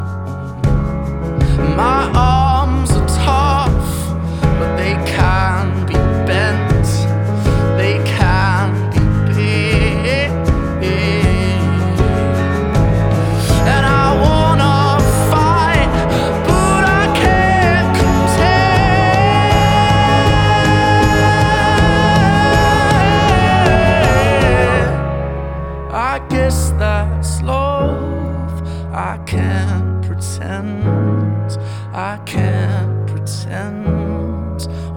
Alternative Rock Adult Alternative
Жанр: Рок / Альтернатива